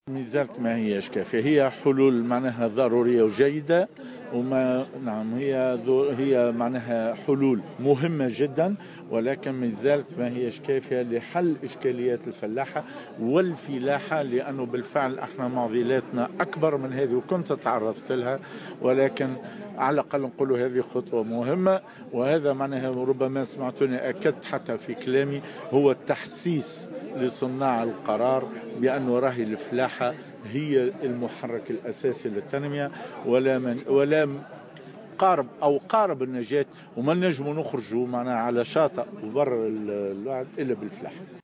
على هامش الاحتفال بالعيد الوطني للفلاحة